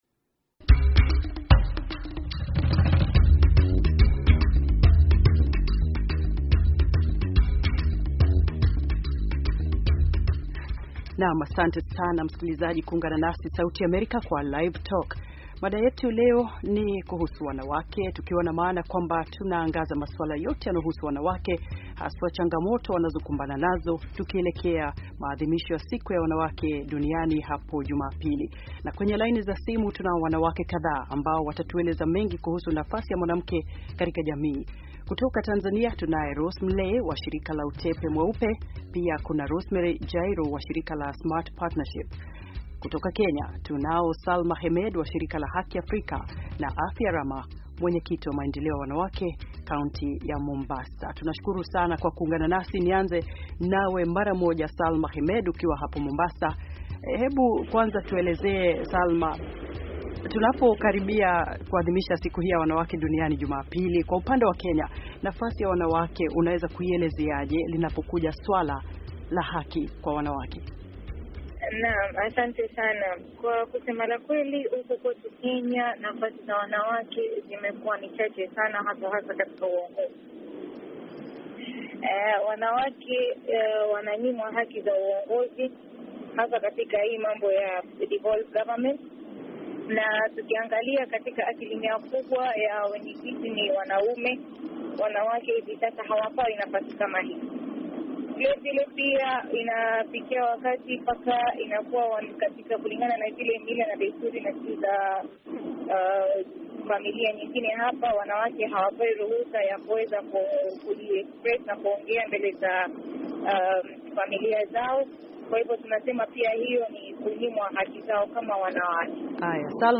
Mjadala: Siku ya Wanawake Duniani 2015